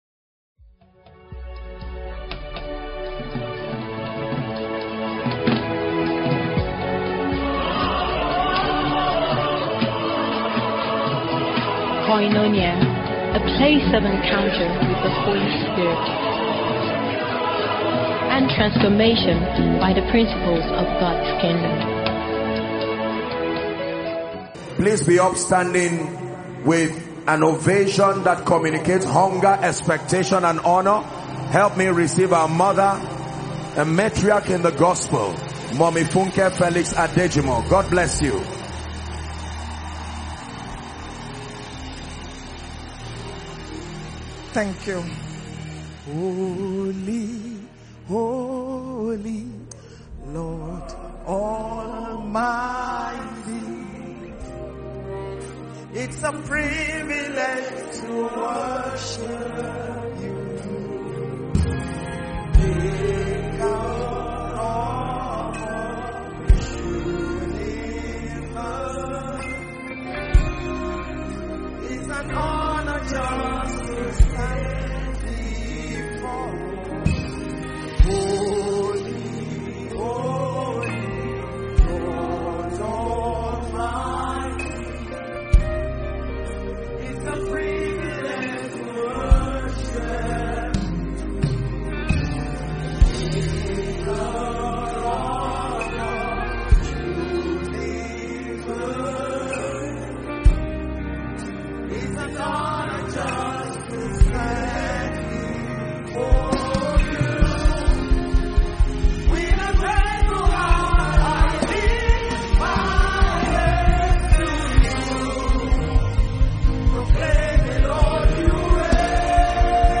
As thousands gathered onsite and across multiple online platforms, it became clear that this night was divinely orchestrated for transformation, alignment, and prophetic activation.
From the opening moments to the final amen, the atmosphere carried a deep hunger for God and a strong sense that Heaven was speaking with clarity and urgency.